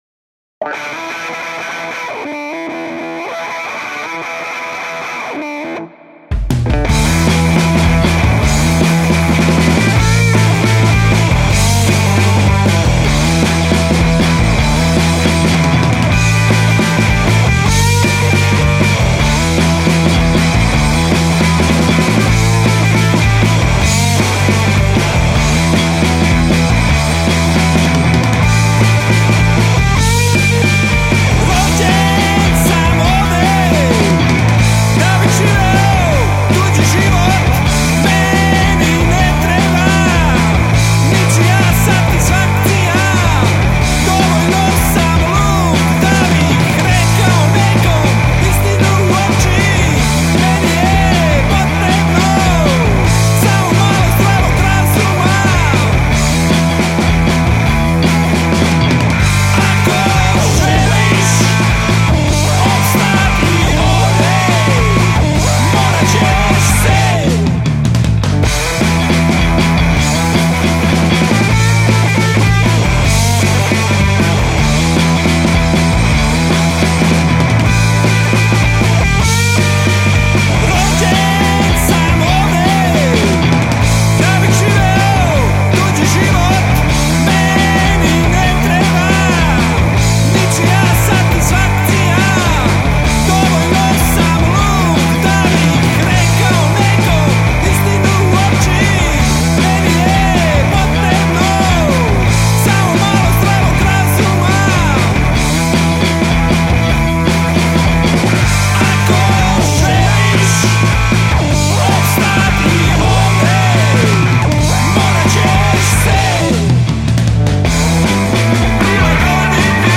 Muzika se može okarakterisati kao garažni rock&roll.